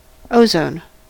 En-us-ozone.ogg